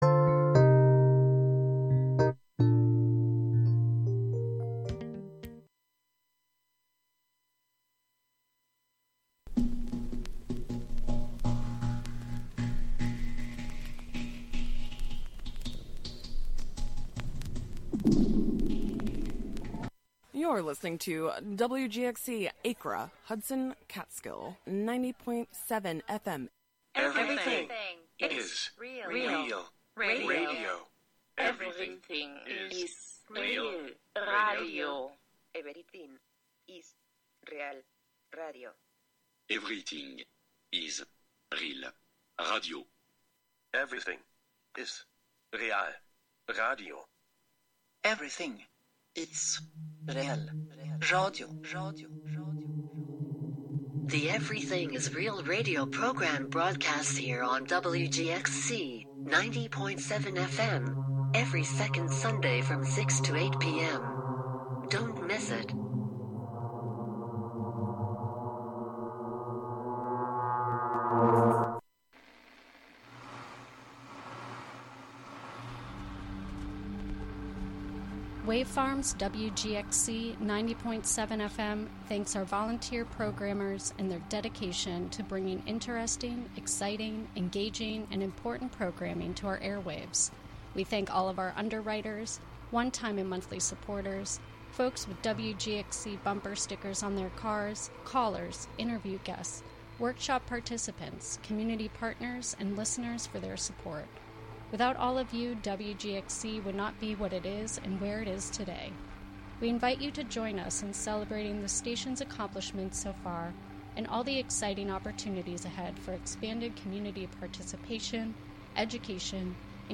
Join us for a Special Roundtable! Listen for the number to call to be part of this historic show.